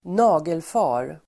Uttal: [²n'a:gelfa:r]